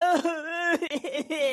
Oddbods Pogo Crying HQ